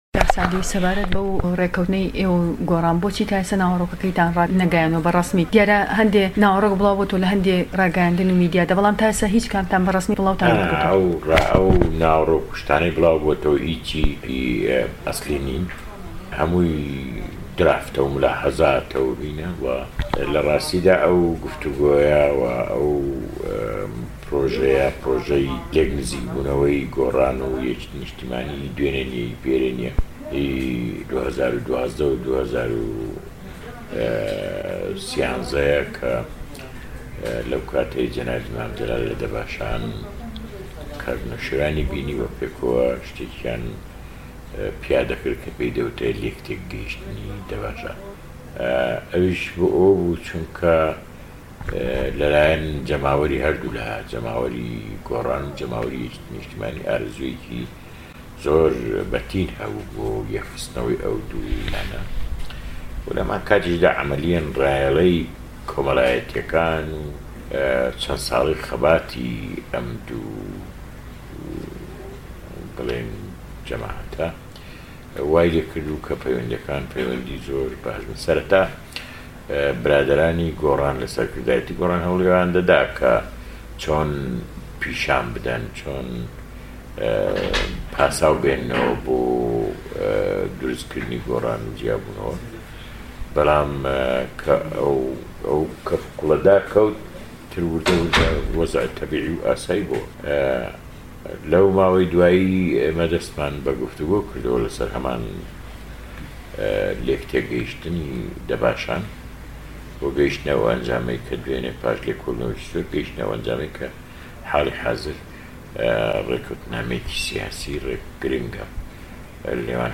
سه‌عدی ئه‌حمه‌د پیره‌ ئه‌ندامی مه‌كته‌بی سیاسی یه‌كێتی نیشتمانی كوردستان له‌م وتووێژه‌ تایبه‌ته‌دا له‌گه‌ڵ ده‌نگی ئه‌مەریكا باس له‌ ڕێكکه‌وتنه‌ سیاسییه‌كه‌ی نێوان حیزبه‌كه‌ی و بزوتنه‌وه‌ی گۆڕان ده‌كات و ئاماژه‌ به‌وه‌ش ده‌كات كه‌ پێویست ناكات ڕێكکه‌وتنه‌كه‌ی نێوانیان به‌ نه‌فه‌سی موئامه‌ره‌ سه‌یر بكرێت.